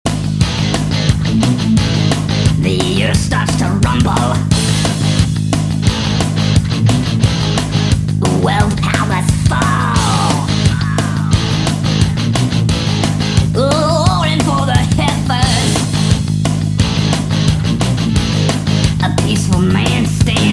мужской голос
жесткие
Драйвовые
электрогитара
из игр